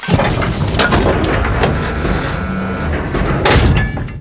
boardship.wav